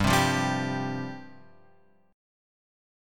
G 7th Suspended 4th